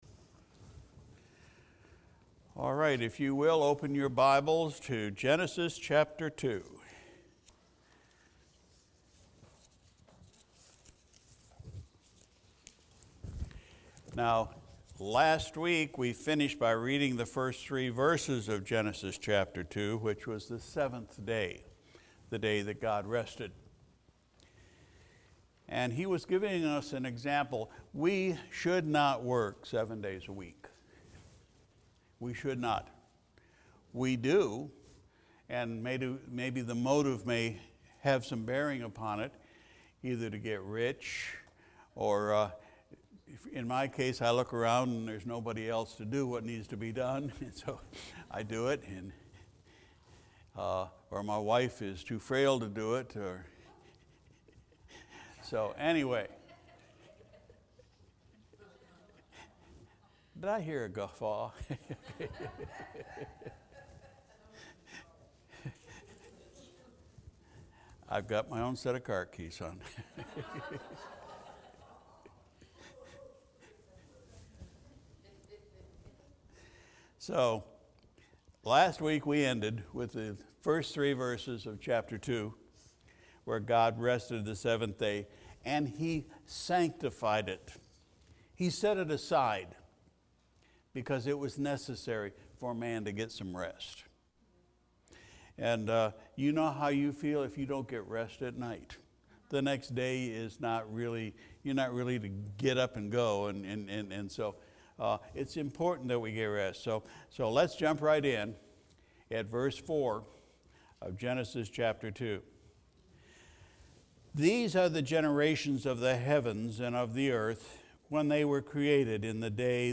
February 28, 2021 Sunday Evening Service We continued our study in the Book of Genesis